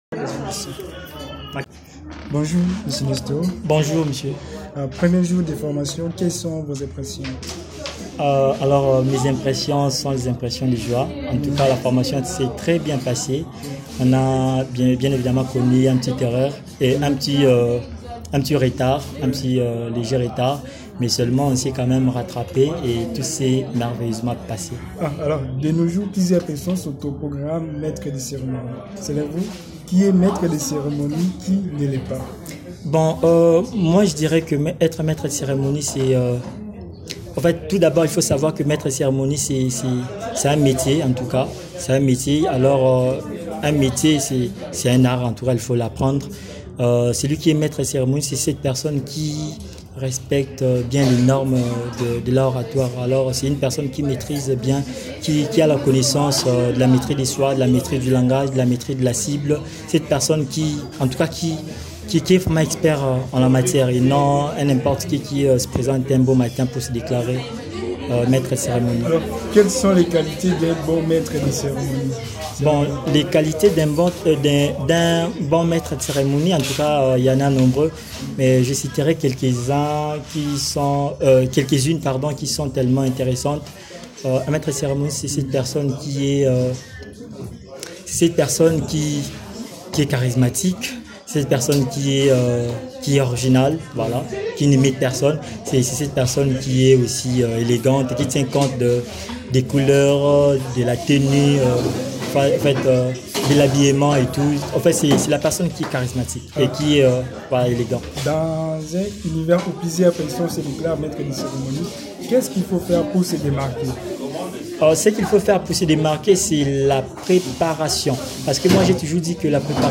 un interview en tant que maître de cérémonie